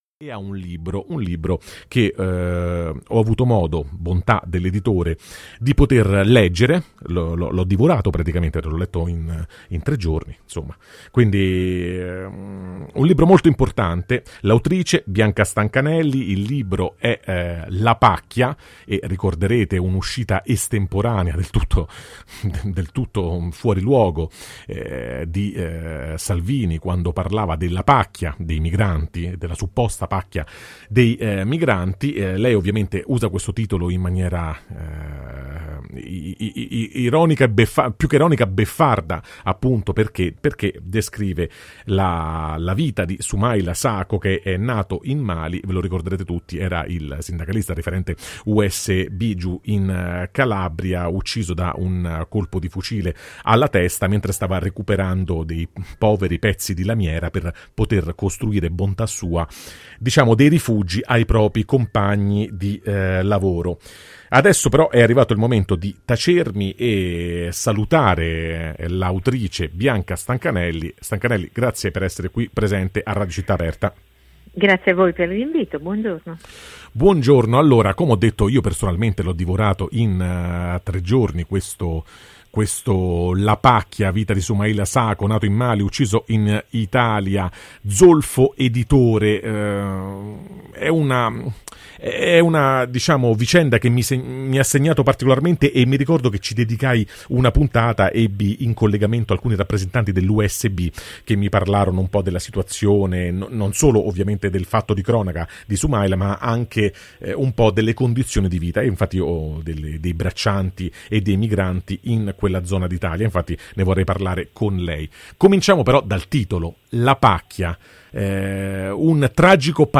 Morti di pacchia: intervista